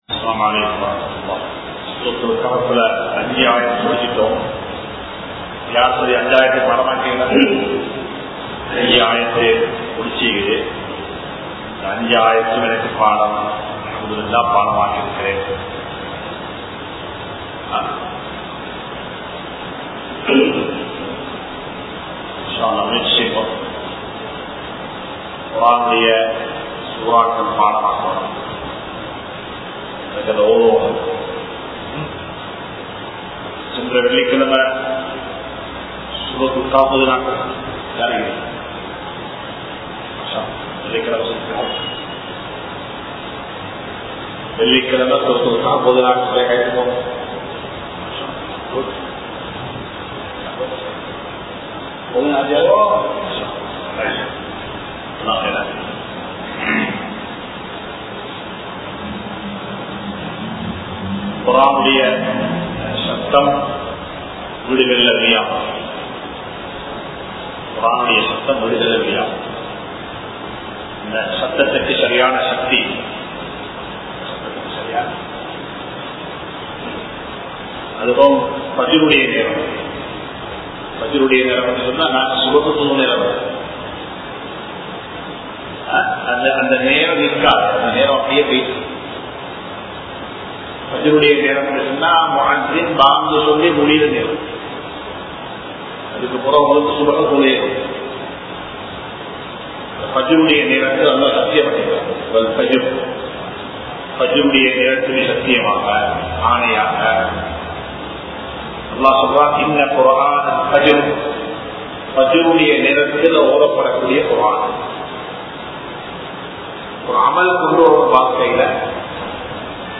Soorathul Kahf Thafseer Part-04 (சூரத்துல் கஹ்ப் தப்ஸீர் பாகம்-04) | Audio Bayans | All Ceylon Muslim Youth Community | Addalaichenai